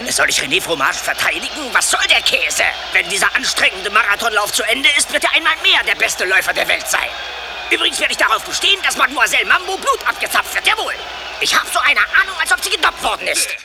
- Klingt wie eine Mischung aus Tobias Meister und Andreas Mannkopff.